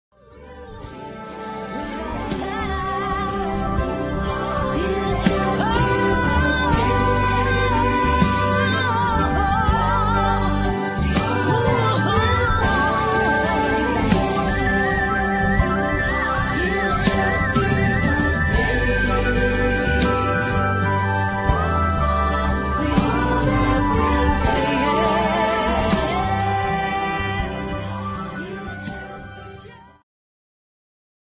Segment Progressive
Rock